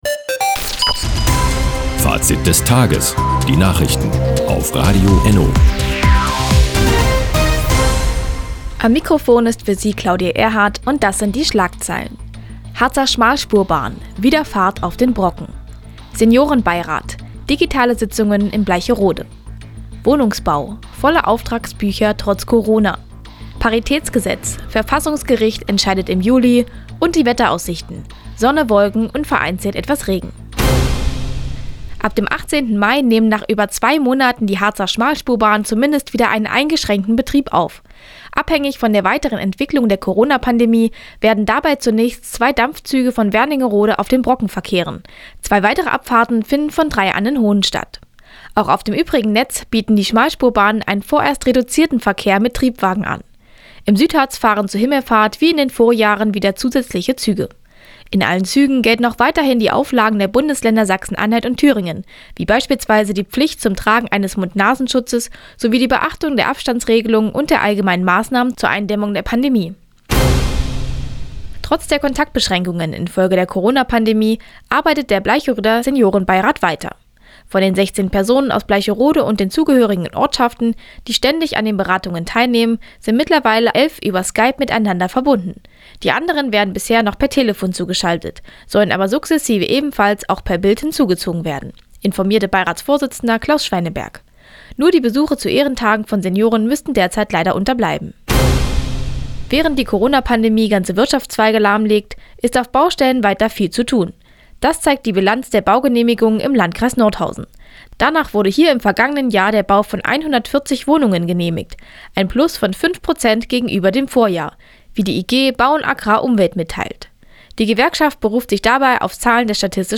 Die tägliche Nachrichtensendung ist jetzt hier zu hören.